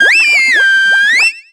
Cri d'Oratoria dans Pokémon Soleil et Lune.